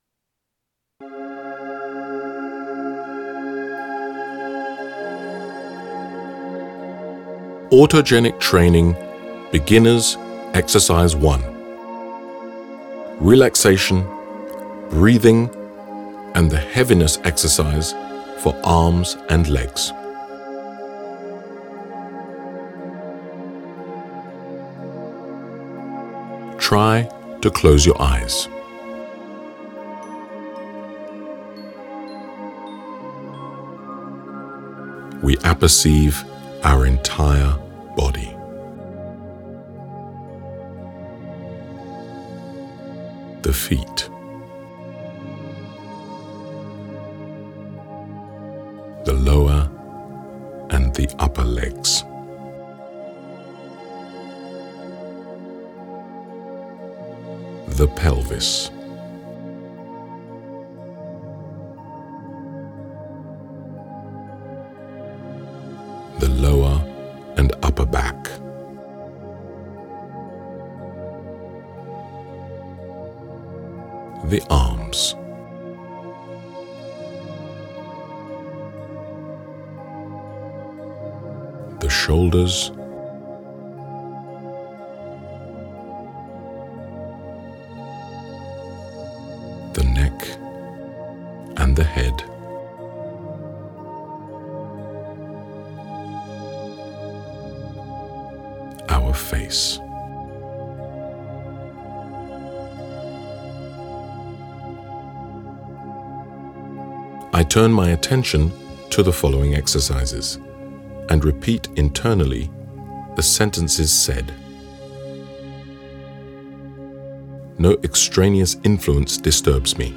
Autogenic training beginner's exercise 1 Relaxation, breathing and the heaviness exercise for arms and legs
from the audiobook Autogenic Training 1 as MP3 download file for free.